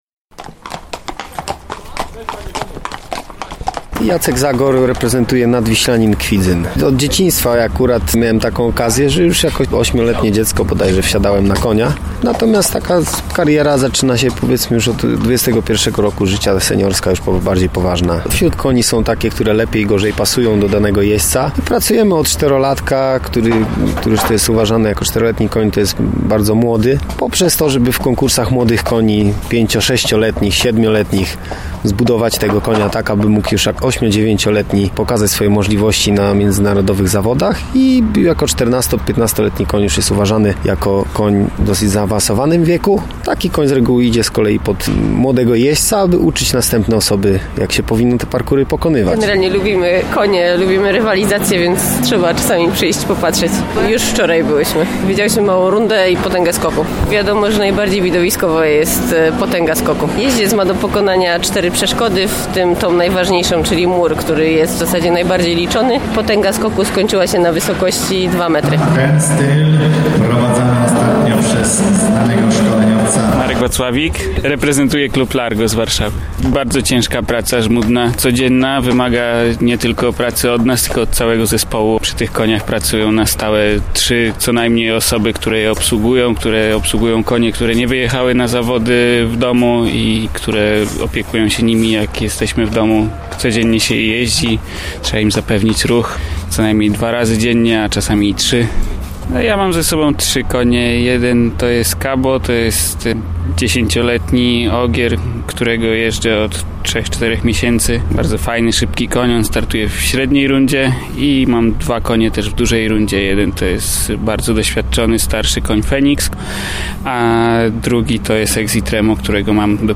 Na półmetku turnieju sprawdziliśmy, jak sportowcy przygotowują siebie i swoje konie do zawodów. Porozmawialiśmy też z publicznością.